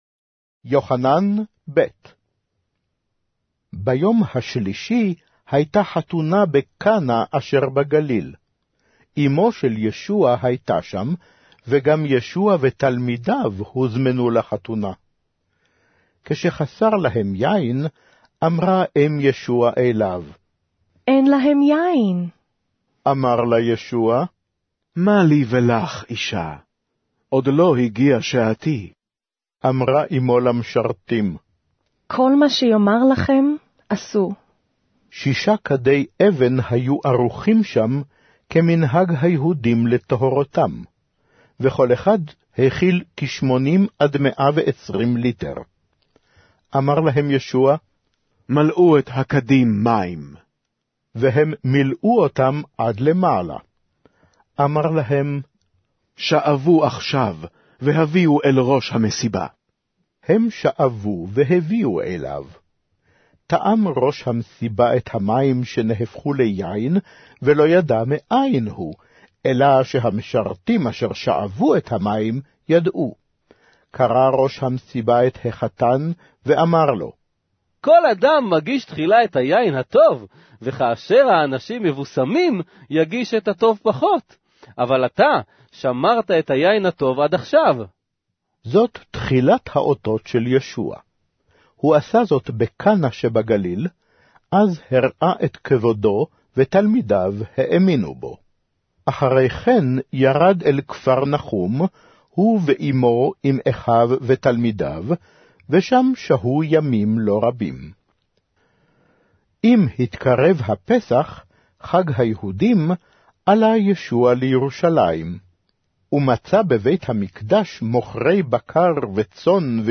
Hebrew Audio Bible - John 6 in Mkjv bible version